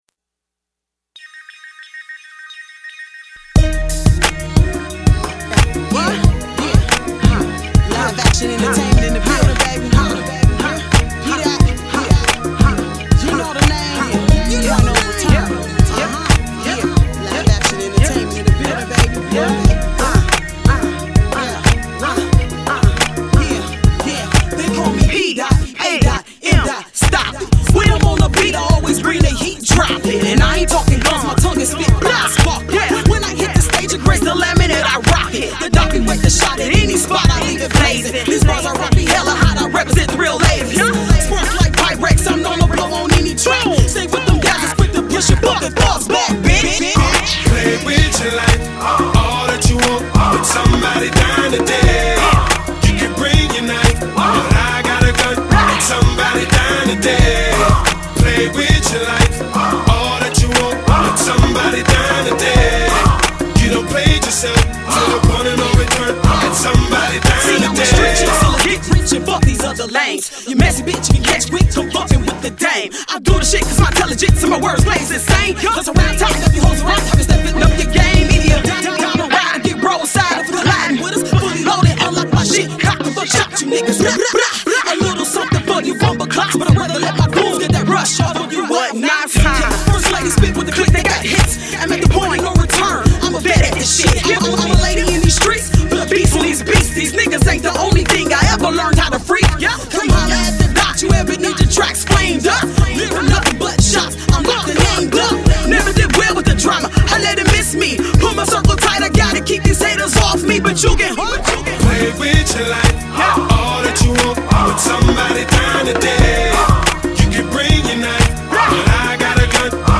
Hiphop
Check her tone, style and flow.